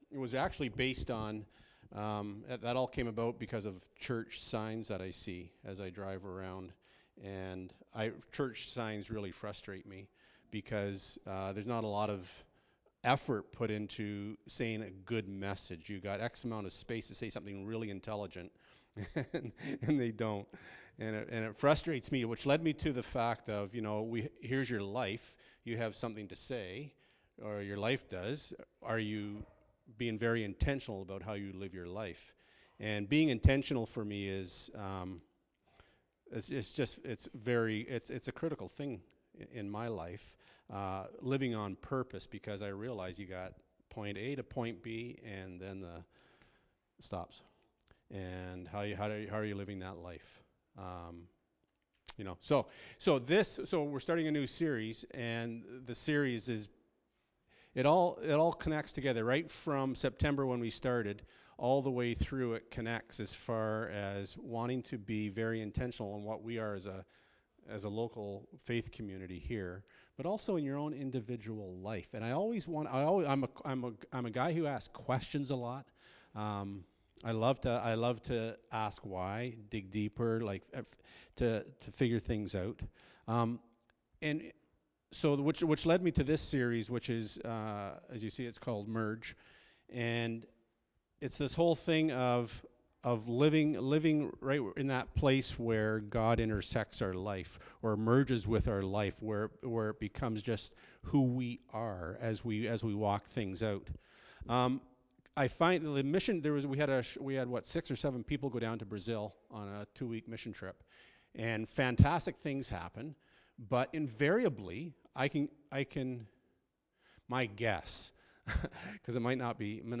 Service Type: Friday Nights